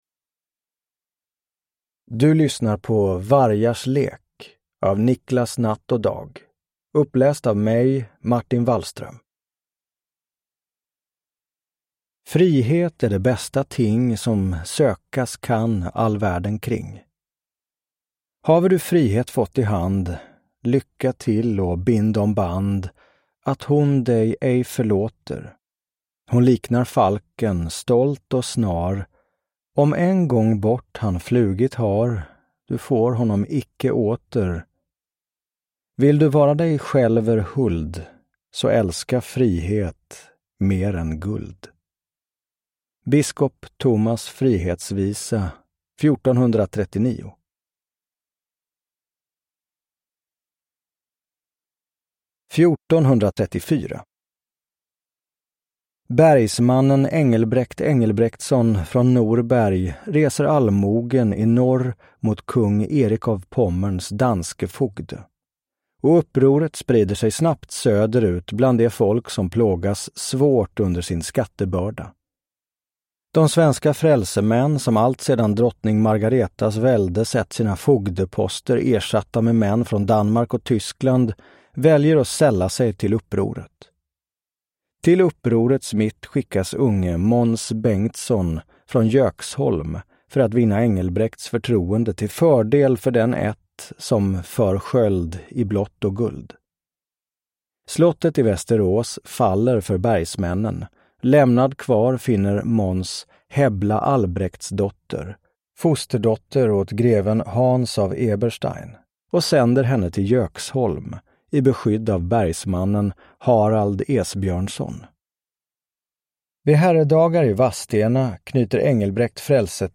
Vargars lek – Ljudbok